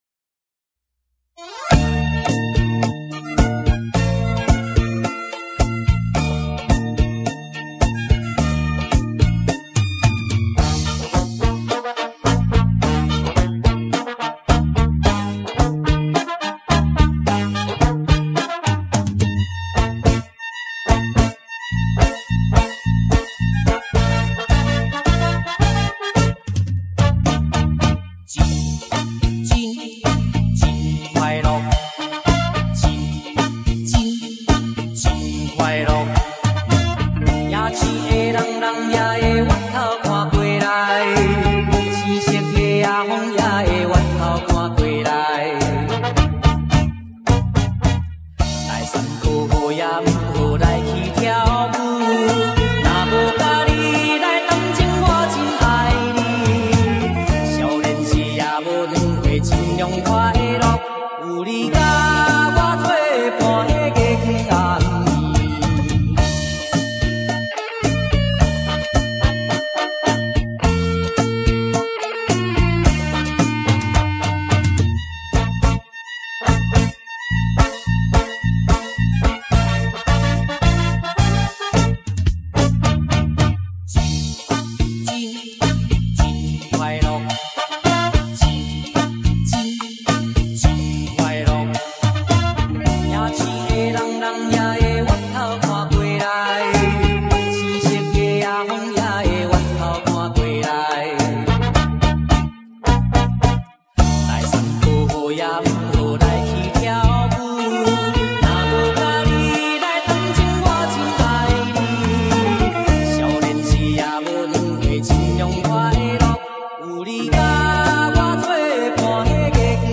MP3華語流行樂合輯DVD版